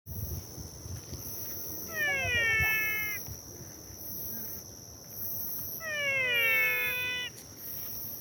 Physalaemus gracilis
Class: Amphibia
Spanish Name: Ranita Gato
Condition: Wild
Certainty: Recorded vocal